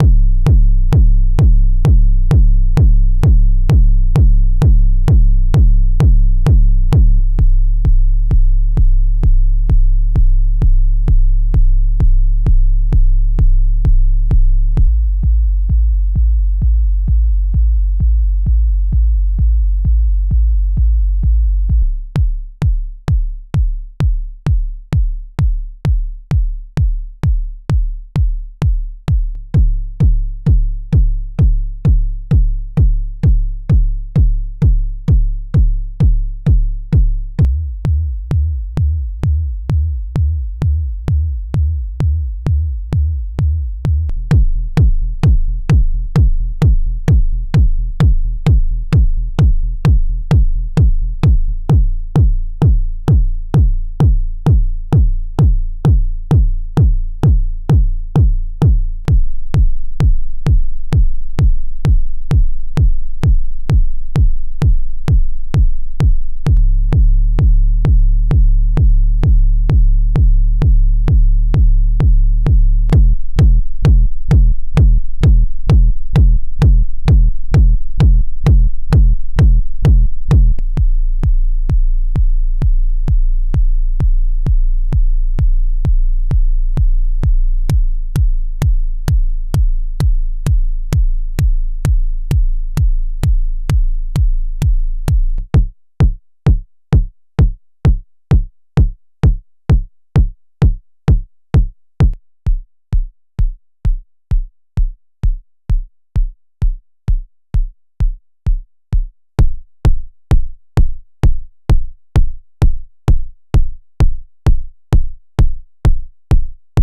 Mais en fait le vrai kick qui tue il est analo, ben ouais ça fait c**** mais c'est comme ça : ça sonne juste pas pareil c'est profond, c'est riche, c'est présent, et tout.
Cette fois-ci le fichier est en 16bits 44.1kHz
ça m'aspire le tympan comme une membrane de caisson  Smiley puissant !  Smiley
Kicksamples2.wav